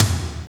TOM TOM260OL.wav